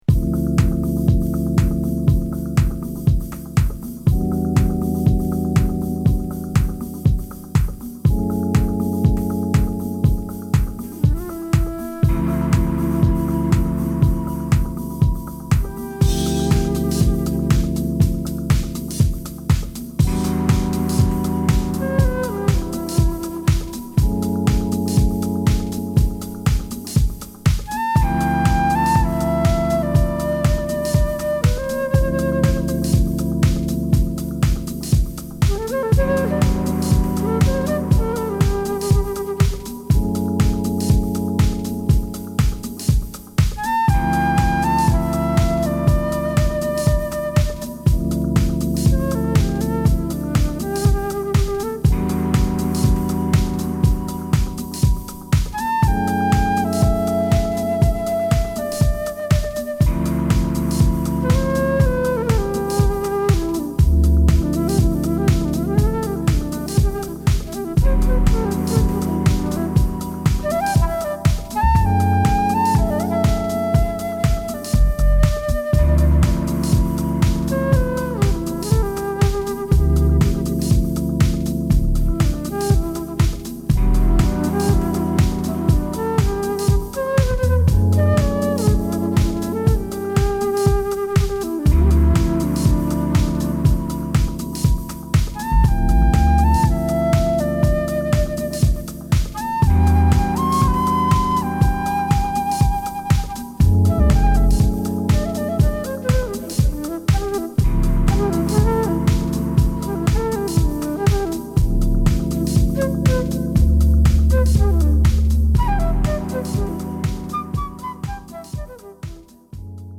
スマートなVOICEサンプルと、ファンキーなハウスビートにセクシーなキーボード。